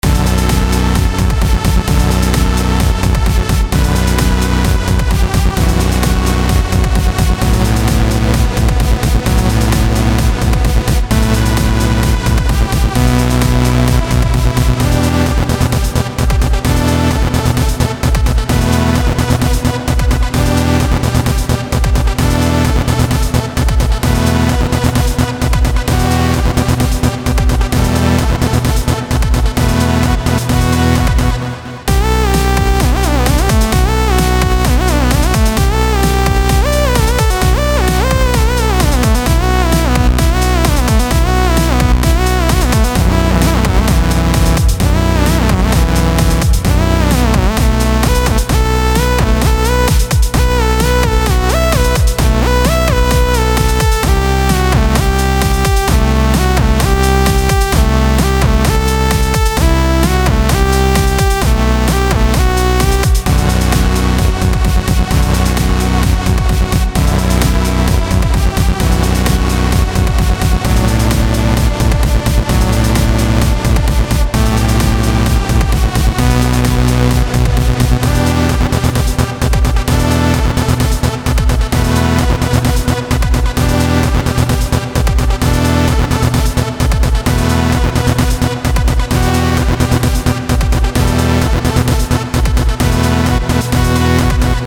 Crevice of Fate - actiony electronic